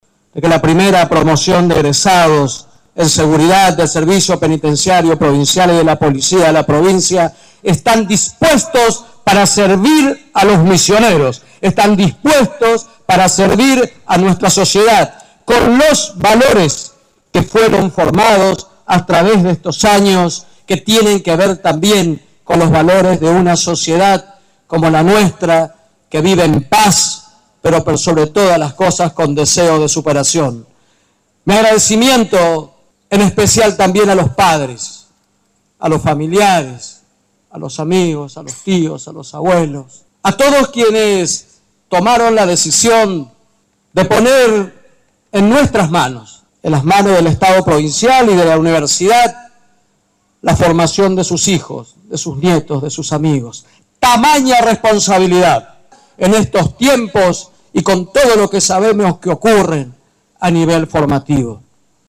La primera promoción de los nuevos licenciados de la Policía y el Servicio Penitenciario de la Provincia tuvo su acto de colación.
Audio Radio LT 17- Oscar Herrera Ahuad